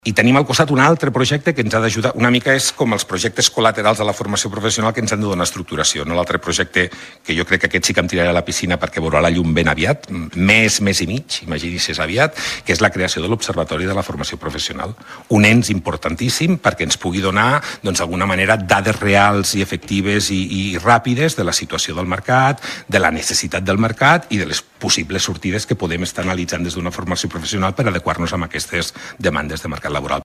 En el decurs de l’entrevista, Bardina ha destacat també el bon moment de la Formació Professional, que ja concentra el 30% de l’alumnat postobligatori.